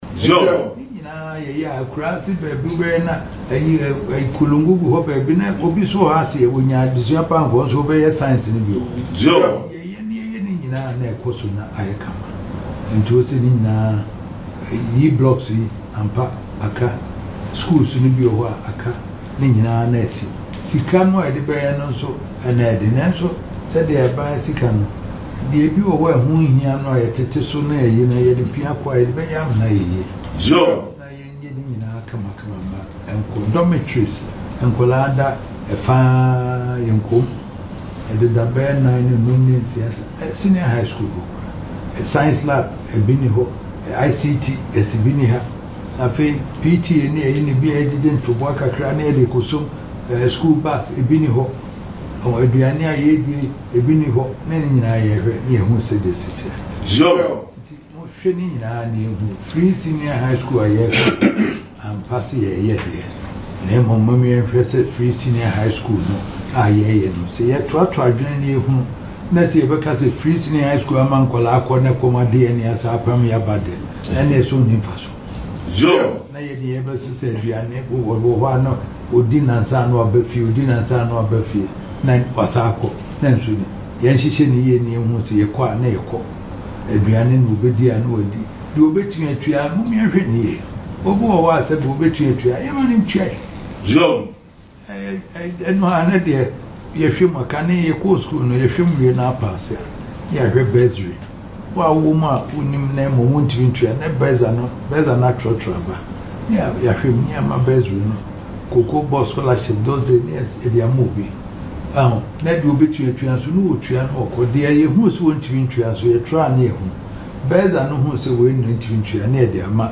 He made the remarks during a courtesy visit by the education minister and the Ashanti Regional Minister to the Manhyia Palace.